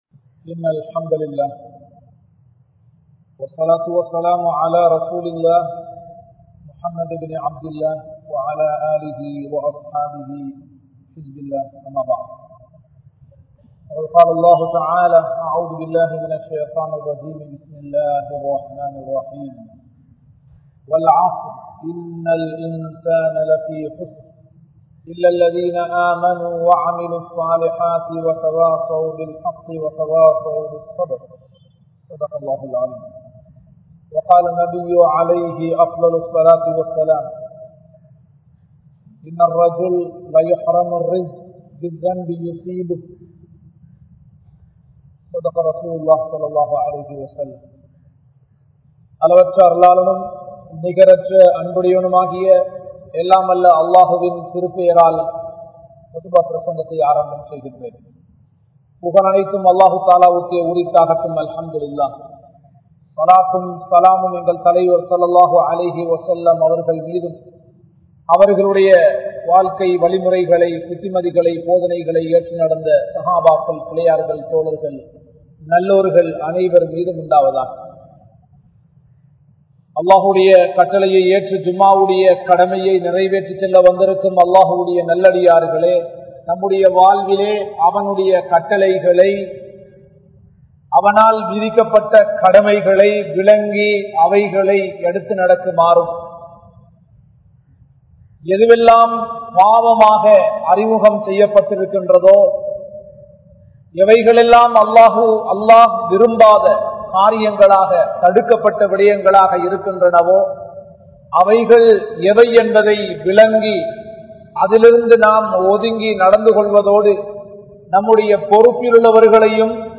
Paavaththin Vifareethangal (பாவத்தின் விபரீதங்கள்) | Audio Bayans | All Ceylon Muslim Youth Community | Addalaichenai
Colombo 12, Aluthkade, Muhiyadeen Jumua Masjidh